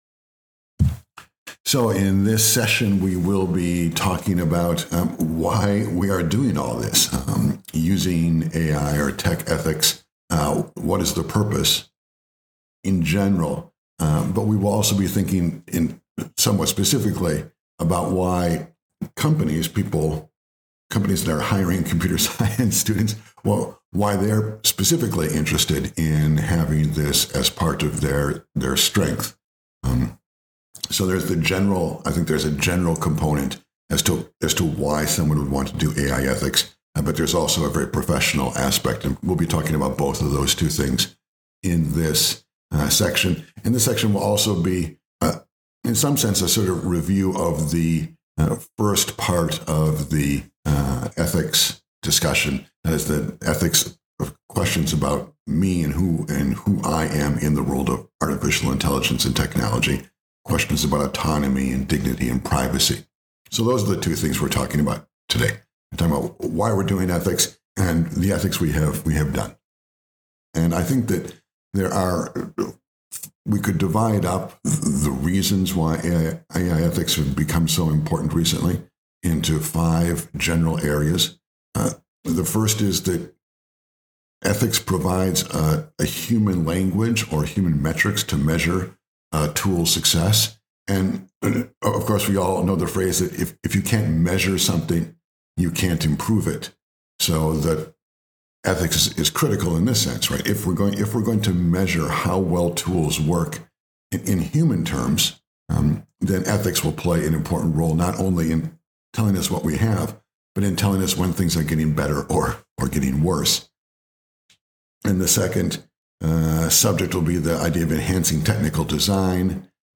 Lecture In this lecture we will use the knowledge tools we gave gained.